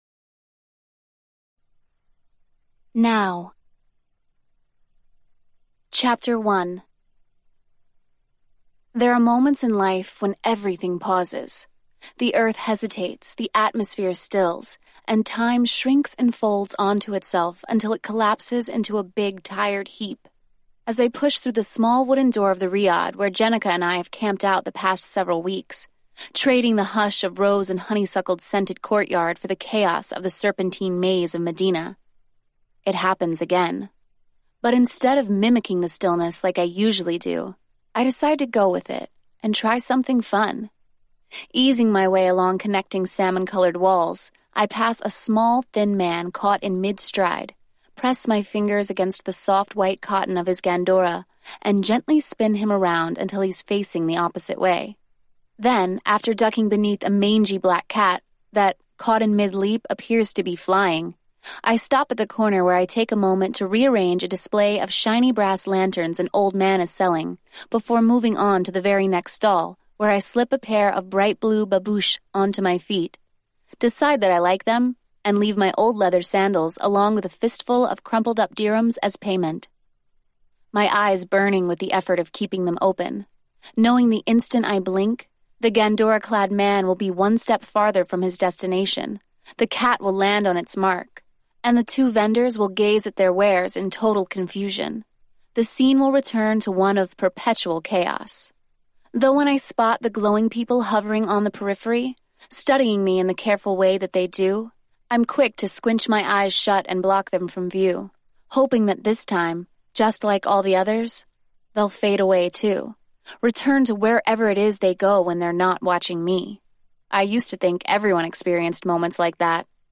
Unabridged — 10 hours, 47 minutes
Audiobook